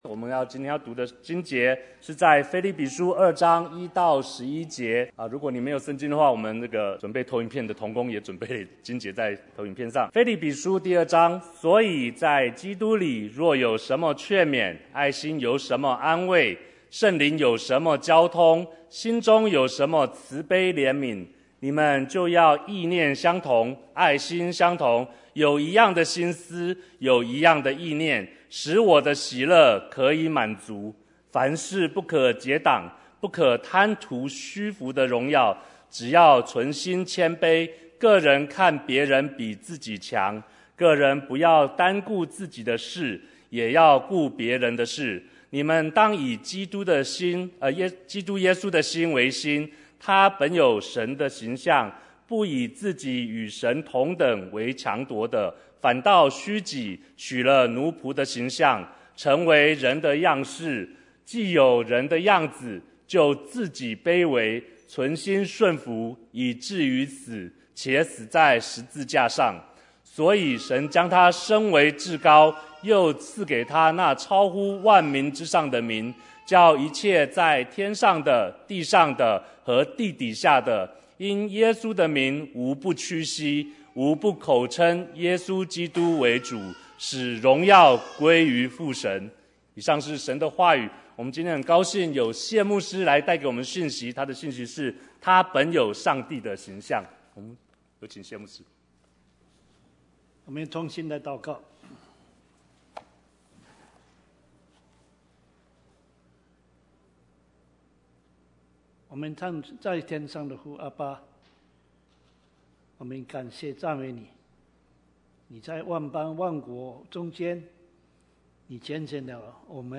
Bible Text: 腓立比書 2:1-11 | Preacher